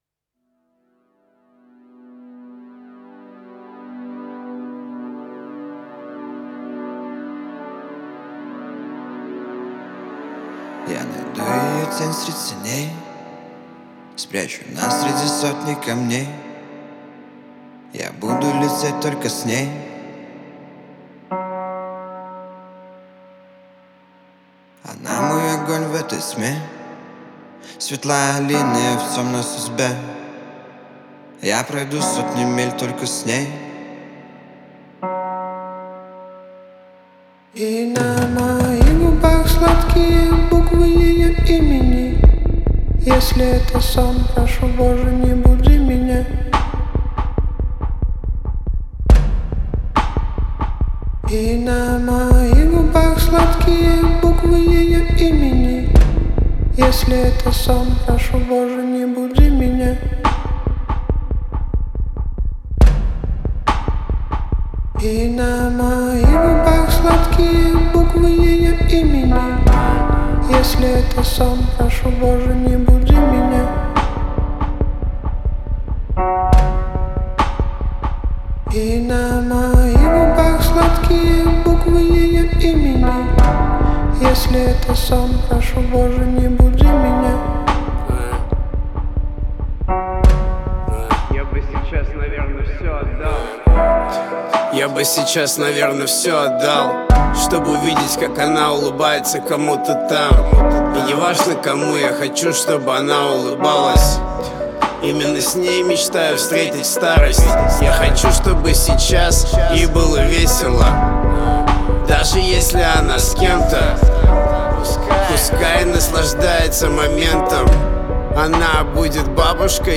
это яркий пример русскоязычного хип-хопа